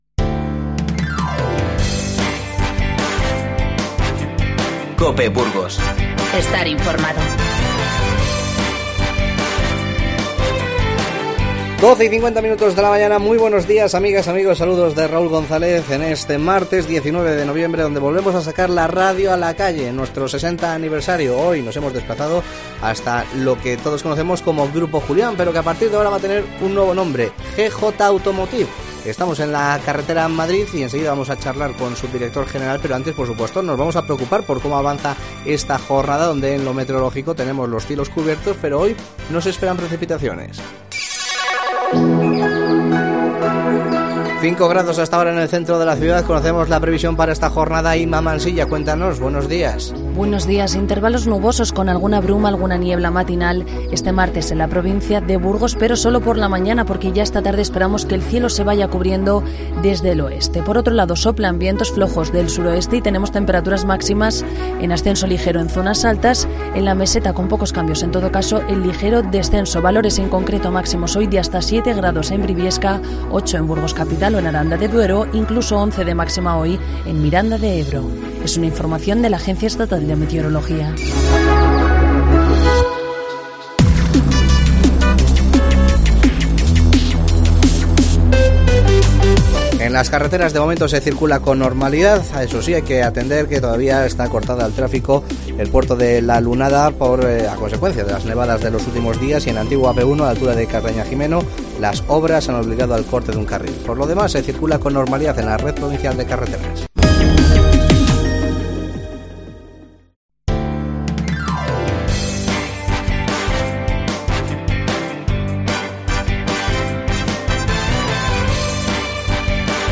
Hoy sacamos de nuevo la radio a la calle. Nos acercamos hasta Grupo Julián, que cambia de denominación. Ahora se llamará GJ Automotive.